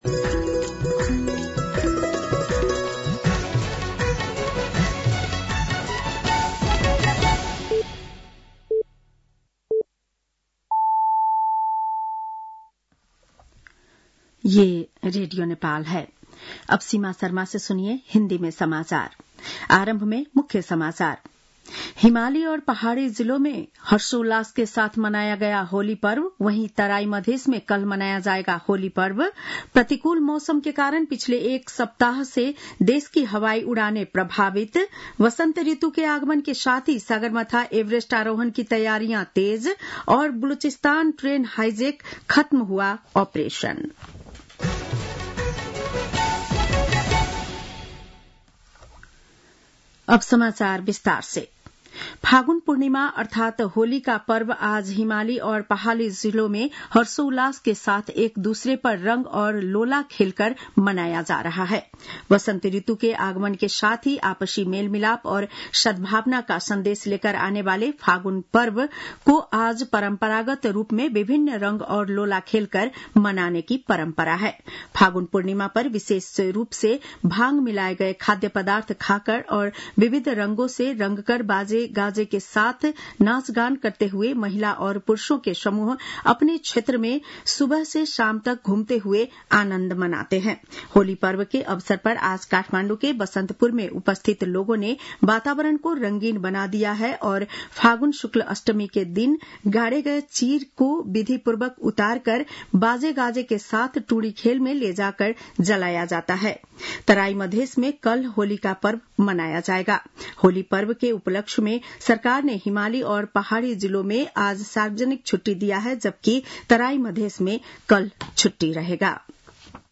बेलुकी १० बजेको हिन्दी समाचार : ३० फागुन , २०८१